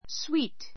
sweet 小 A1 swíːt ス ウィ ー ト 形容詞 ❶ （味が） 甘 あま い 関連語 bitter （苦い）, sour （すっぱい）, hot （辛 から い） sweet cakes sweet cakes 甘いケーキ taste sweet taste sweet 甘い味がする, （味が）甘い I like sweet things very much.